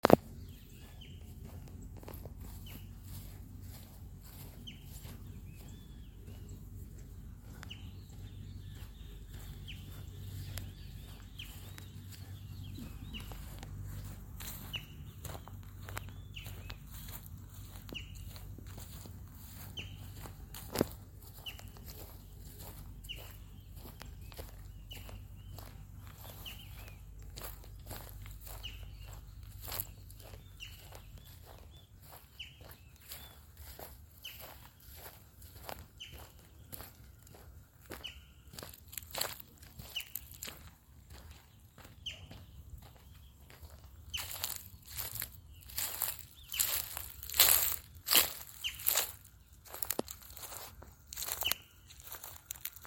Scientific name: Colaptes melanochloros melanolaimus
English Name: Green-barred Woodpecker
Location or protected area: San Miguel, capital
Condition: Wild
Certainty: Recorded vocal